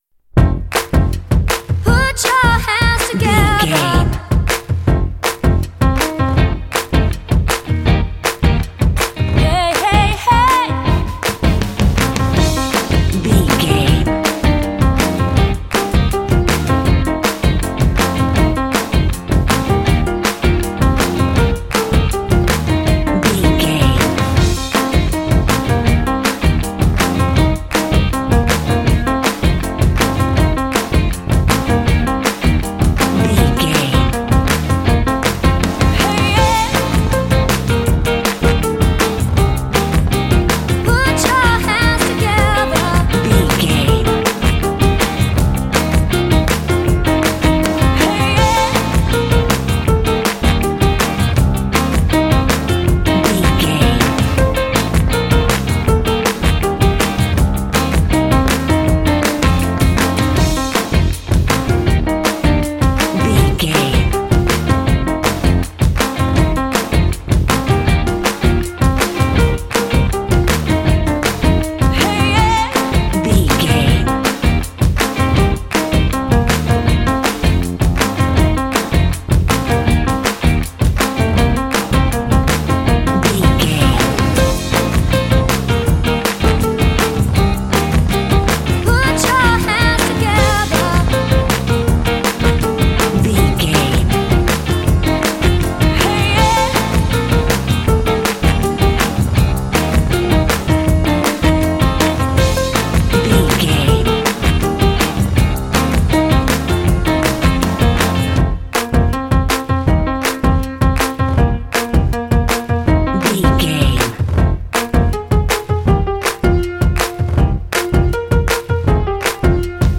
Uplifting
Mixolydian
funky
smooth
groovy
driving
bass guitar
piano
drums
percussion
electric guitar
vocals
Funk
soul